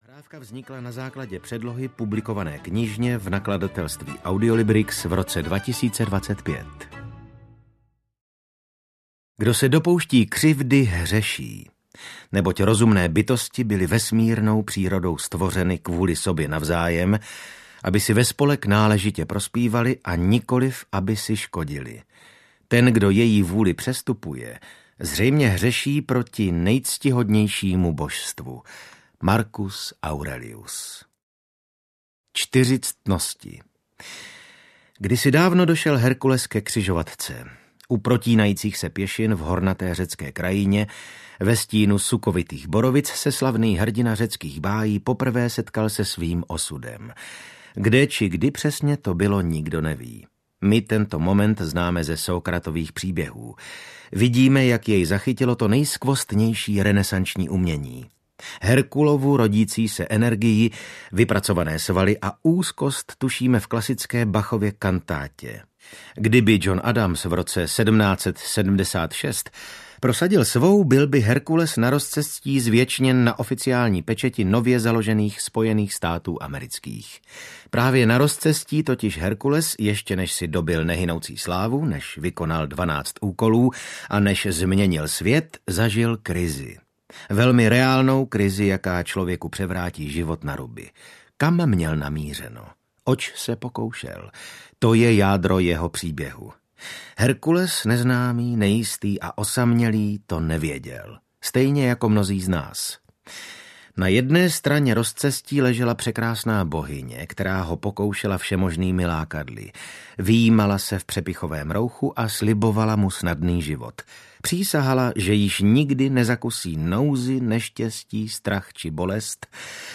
Pravé činy právě teď audiokniha
Ukázka z knihy
• InterpretVasil Fridrich